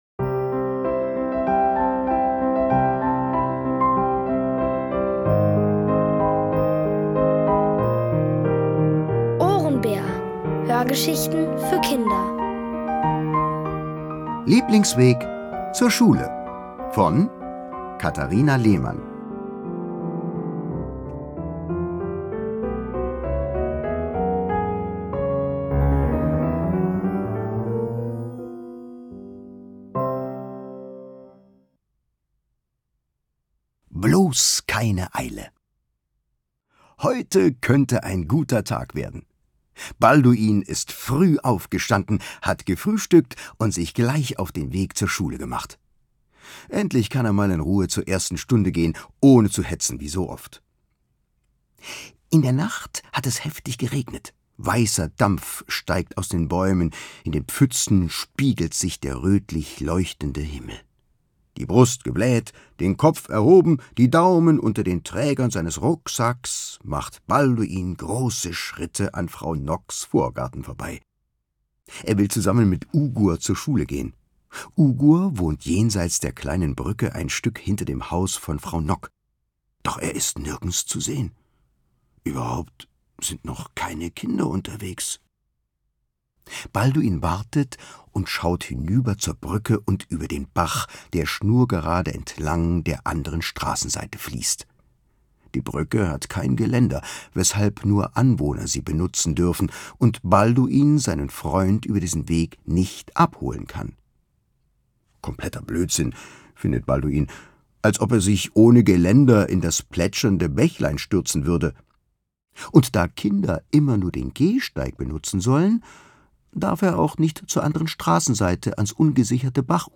Von Autoren extra für die Reihe geschrieben und von bekannten Schauspielern gelesen.
Hörgeschichten empfohlen ab 6: